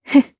Tanya-Vox_Happy1_jp.wav